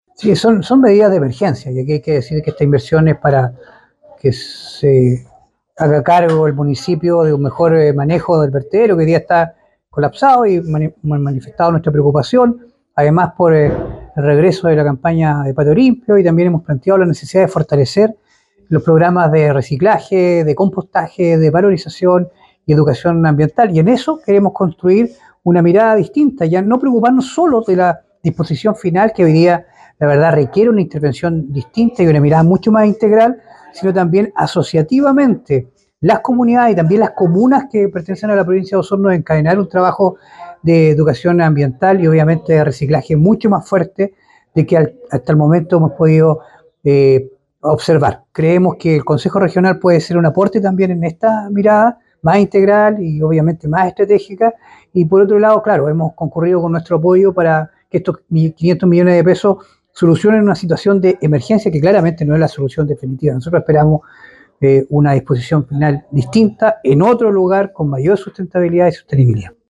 Al respecto, el presidente de la comisión de Medio Ambiente y Cambio Climático del Consejo Regional, Francisco Reyes, indicó que “ hemos planteado la necesidad de fortalecer los programas de reciclaje, de compostaje, de valorización y educación ambiental, y en eso queremos construir una mirada distinta, ya no preocuparnos solo de la disposición final, que hoy día requiere una intervención distinta y una mirada mucho más integral, sino también asociativamente con las comunidades y también las comunas que pertenecen a la provincia de Osorno”.
04-octubre-23-Francisco-Reyes-Emergencia.mp3